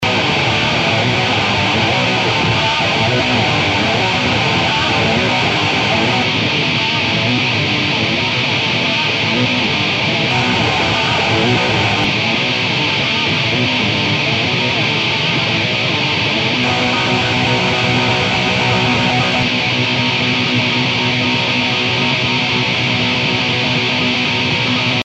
en gros, il y a un high-pass à 90Hz
et des notch vers 190, 770 et 1500Hz (de quelques dB seulement) et puis un low-pass vers 7kHz
je pense que tu entendras toi-même la différence assez significative entre une prise brute et la prise corrigé
dans l'ordre : sonbrut-corrigé-brut-corrigé-brut et corrigé
gratte_clean.mp3